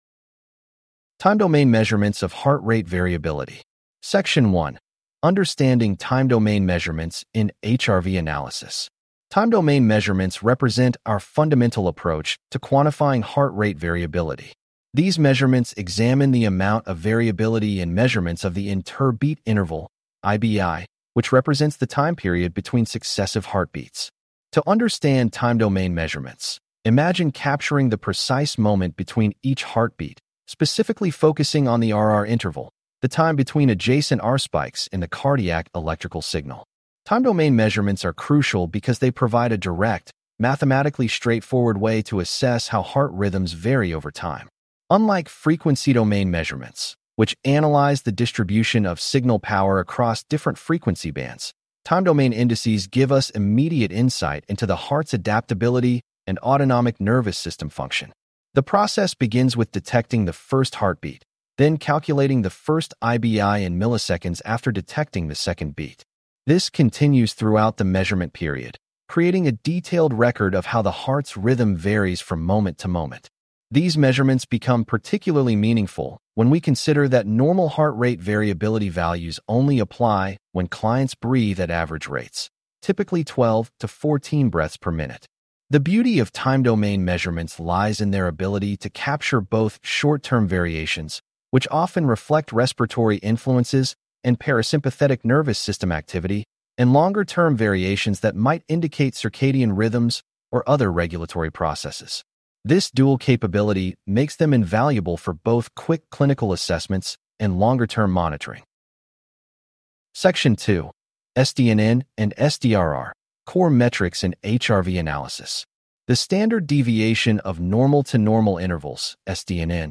🎧 Chapter Lecture: Time-Domain Measurements How Time-Domain Indices Work This section introduces the fundamental building block of time-domain HRV: the interbeat interval.